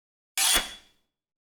SWORD_20.wav